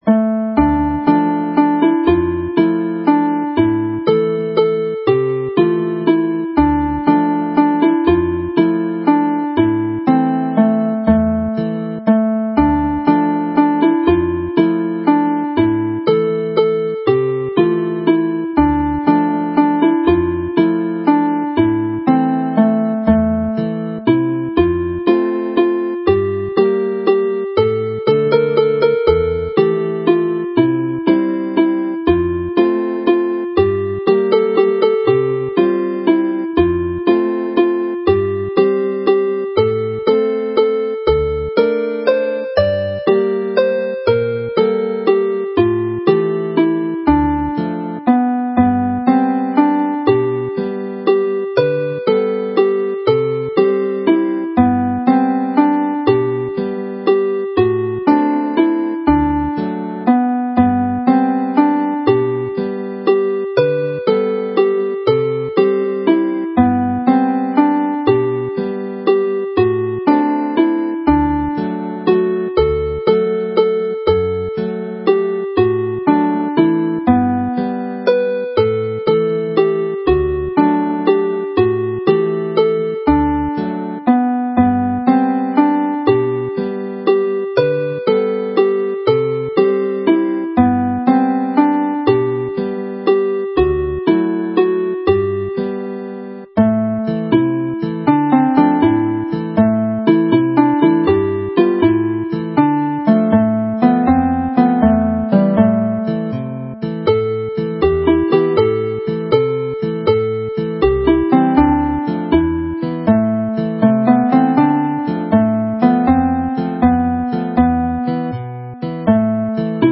This set comprises two traditional Welsh tunes and a more recent one.